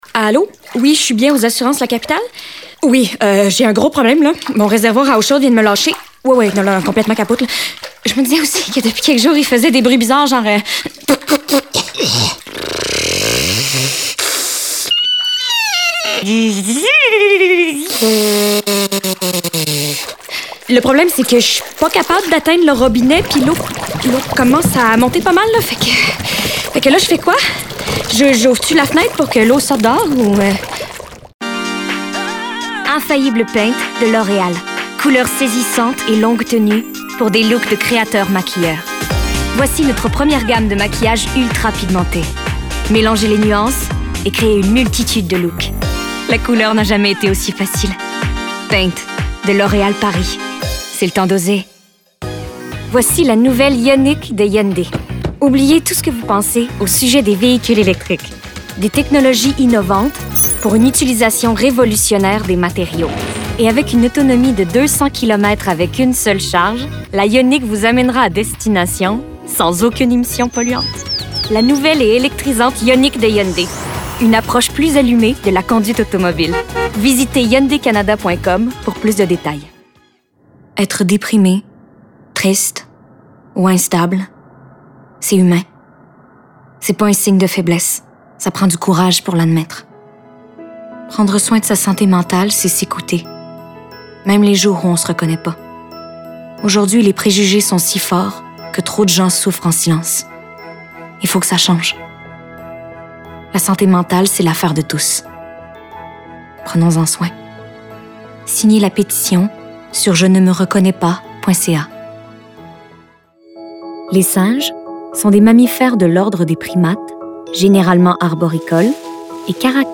DÉMO VOIX
Comédienne